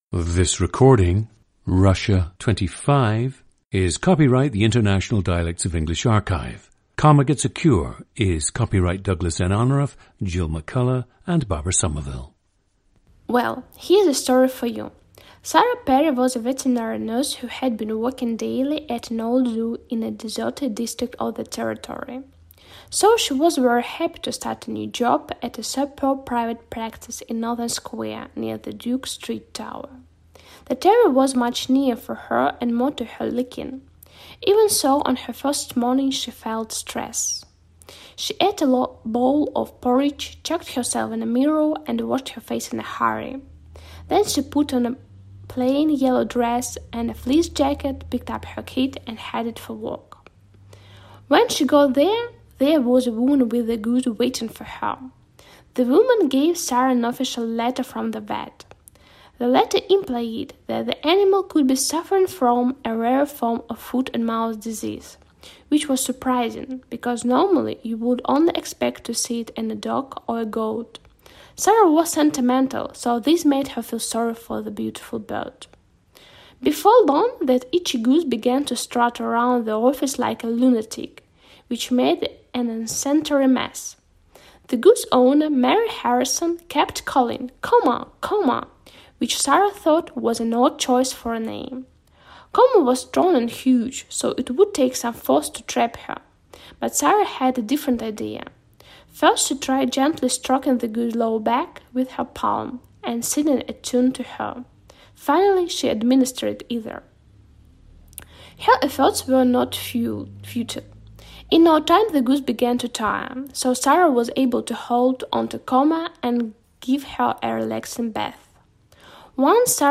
GENDER: female
Some words have uncommon stressing, such as “territory” and “superb.” There is a lack of aspiration, and the “R” sound is more typical of “vibrating” languages. Lastly, there is no specific linking, and speech modification such as “H-dropping” and “T/D-flapping” are present.
• Recordings of accent/dialect speakers from the region you select.
The recordings average four minutes in length and feature both the reading of one of two standard passages, and some unscripted speech.